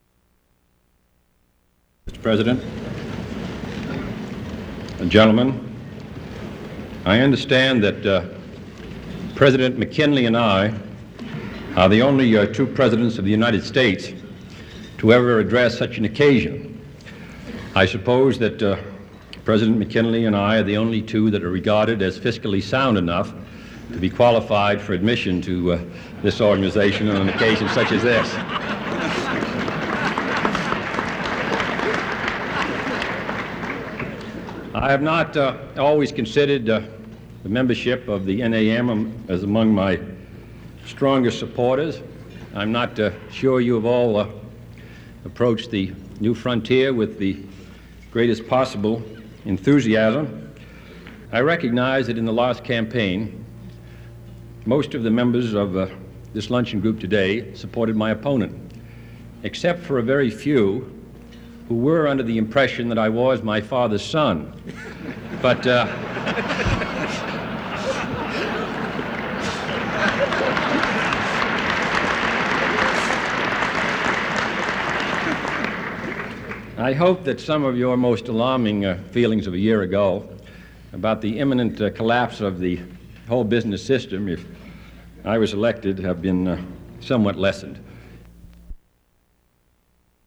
Excerpt of an address by U.S. President John F. Kennedy to the National Association of Manufacturers' luncheon